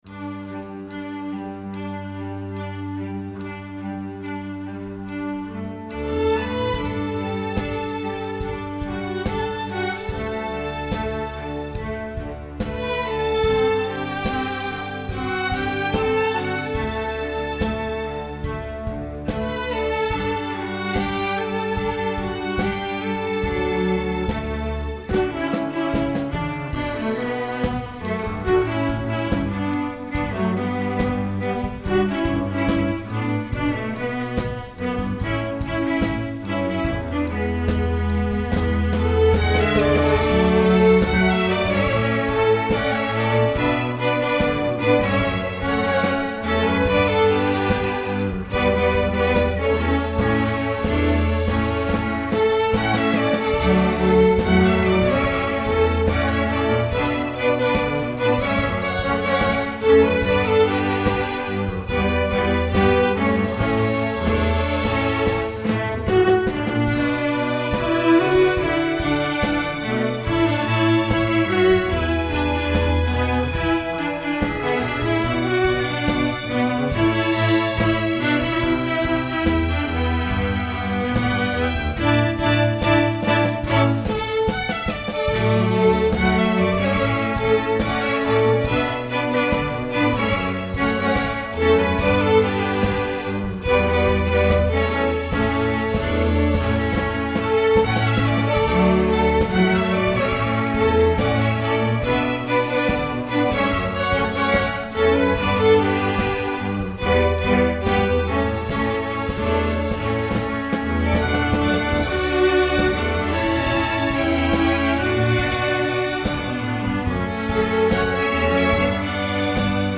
pop, children, metal, rock, r & b, dance, instructional